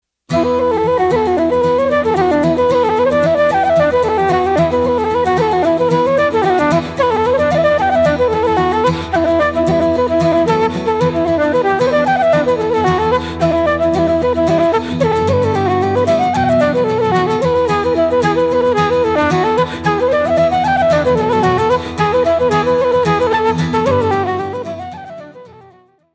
Flutes D, Eb, C, Bb - Low whistles F, D
Guitars
Fiddle, Piano & Harmonium
Wire Strung Harp
Double Bass
Bodhrán